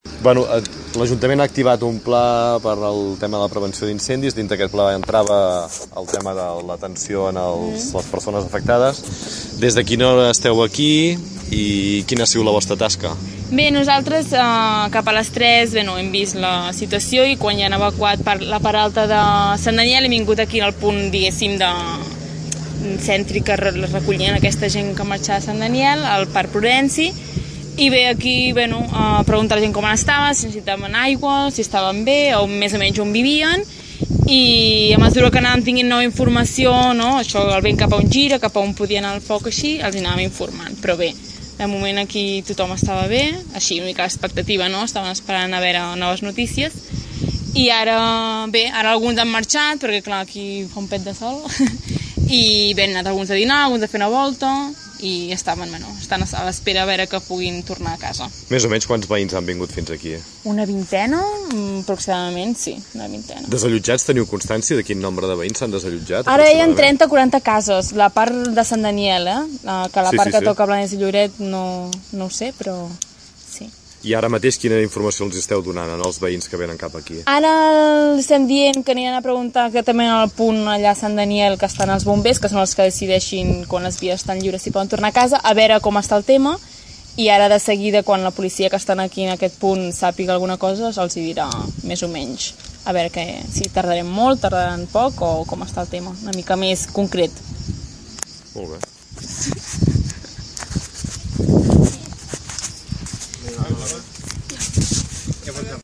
La regidora d’Esports i Sanitat de l’Ajuntament de Tordera, Bàrbara Vergés, ha explicat per Ràdio Tordera el dispositiu que s’ha posat en marxa quan s’ha conegut l’incendi i s’ha vist convenient desallotjar la part nord de la urbanització Sant Daniel de Tordera.